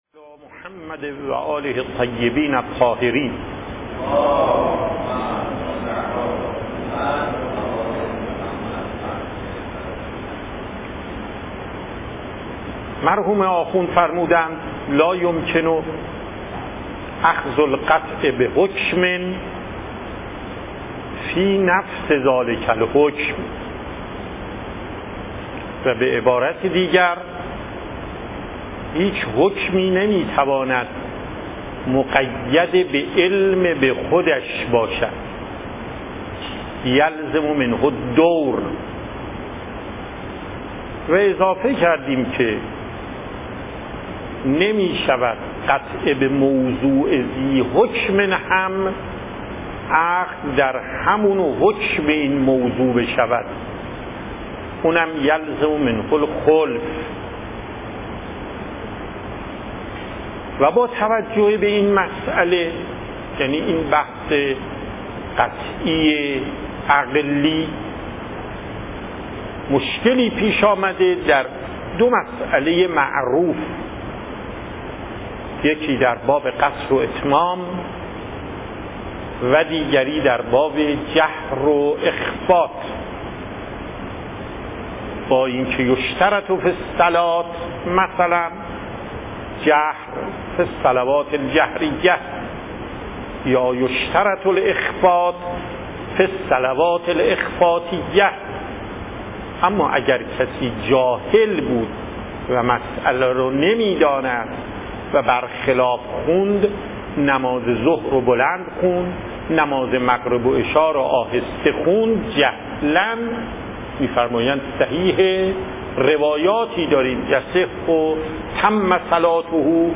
صوت و تقریر درس
درس اصول آیت الله محقق داماد